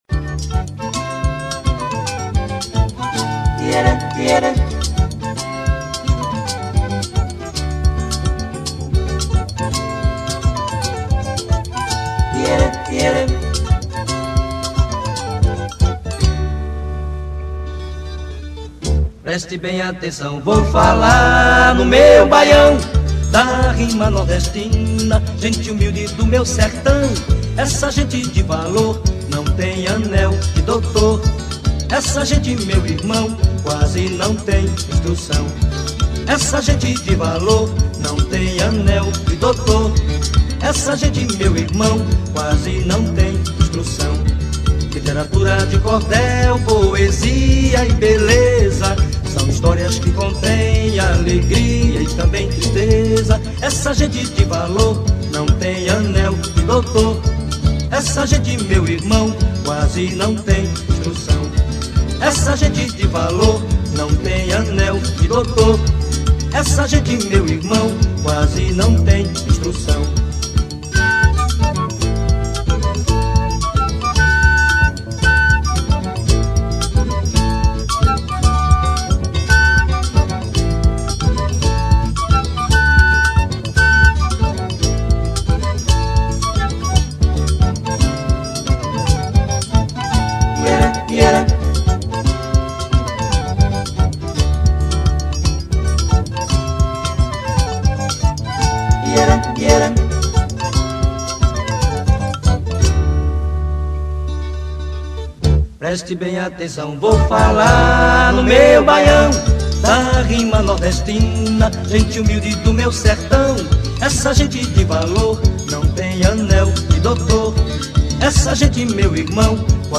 voz e violão
contrabaixo
viola e cavaquinho
flauta